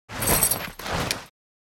Tactical-gear-bag-drop-impact.mp3